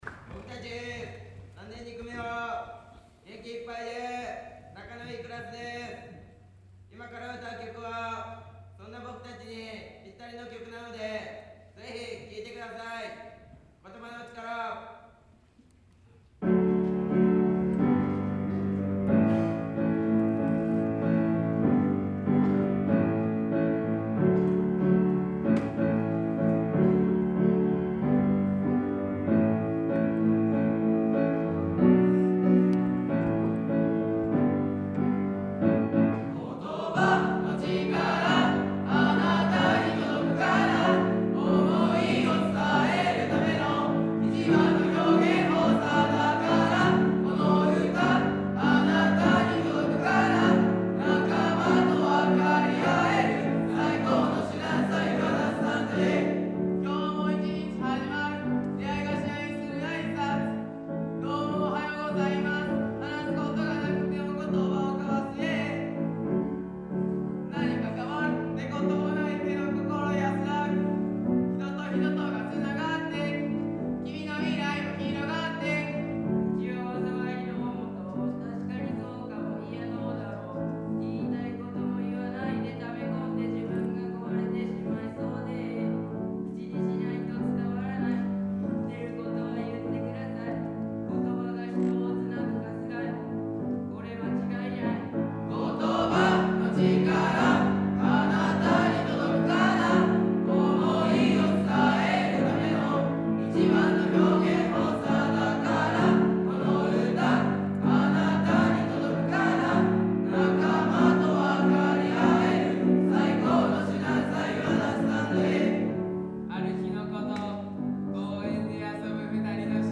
クラス合唱　音楽科発表
平成30年度 学習発表会 3-1合唱